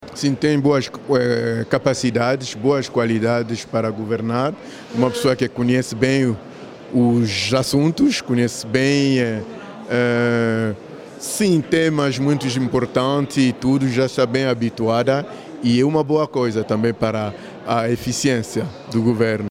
Ricardo Lumengo, deputado federal fala da nova ministra Simonetta Sommaruga.